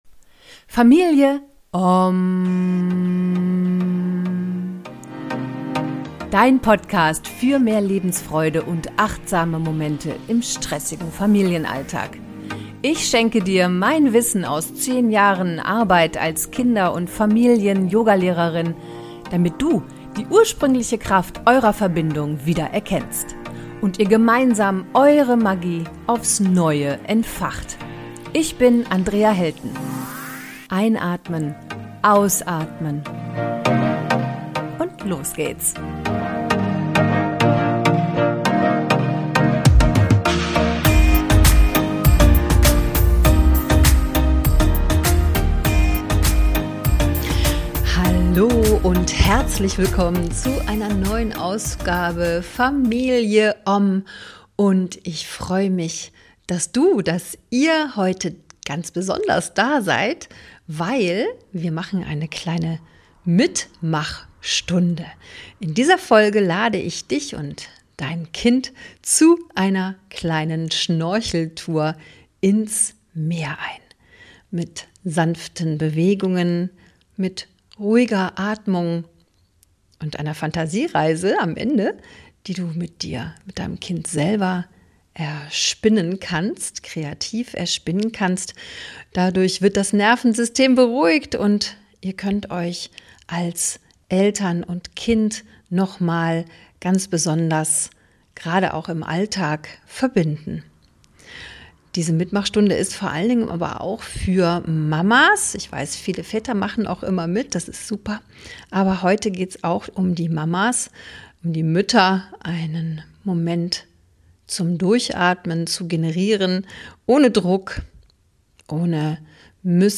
#125 Schnorcheltour im Meer – Eine regulierende Eltern-Kind-Yoga-Mitmachstunde (ohne Musik) ~ Familie Ommm Podcast